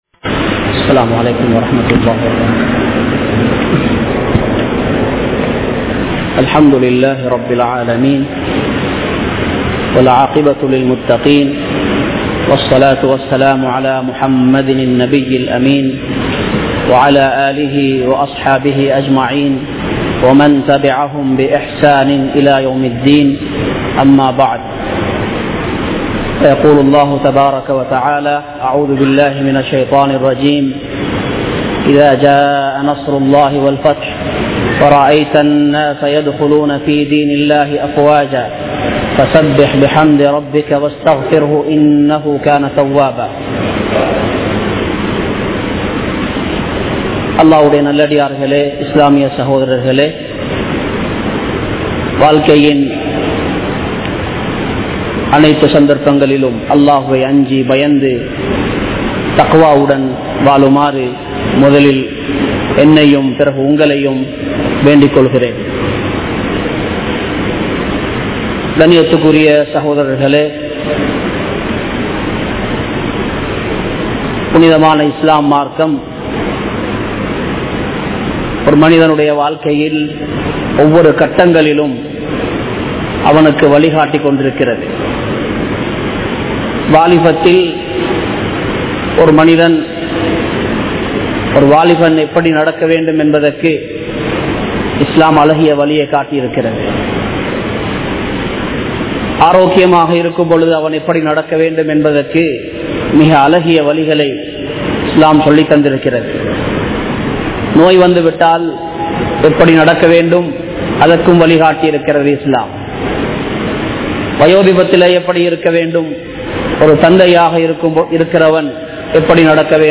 Maranaththitku Mun Seiya Veandiyavaihal (மரணத்திற்கு முன் செய்ய வேண்டியவைகள்) | Audio Bayans | All Ceylon Muslim Youth Community | Addalaichenai
Dehiwela, Muhideen (Markaz) Jumua Masjith